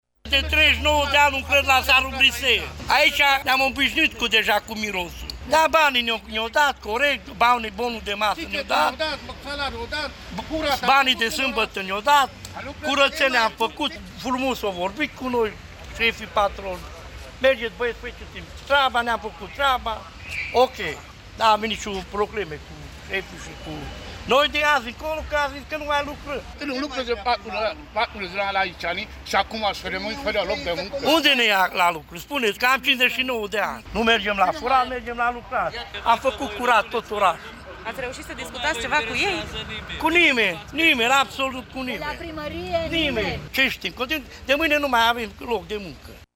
stiri-5-iul-vox-protest.mp3